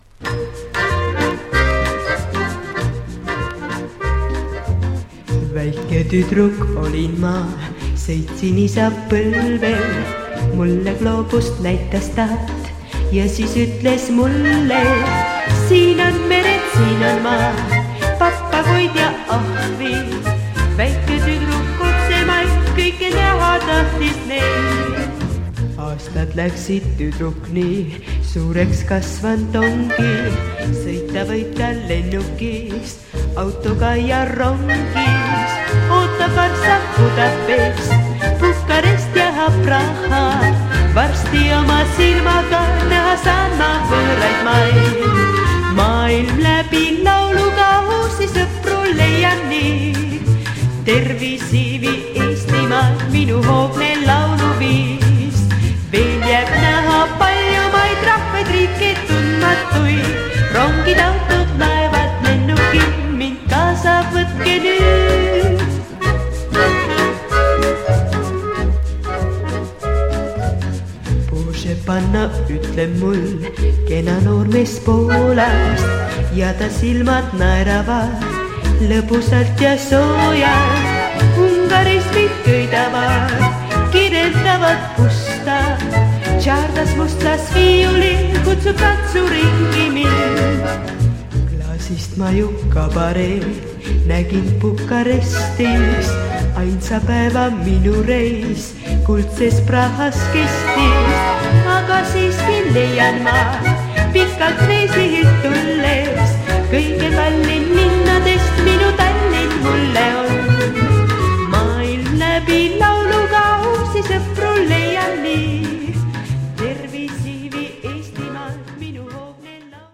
1970年代初頭のエストニアのポップミュージックを集めたコンピレーションの2作目。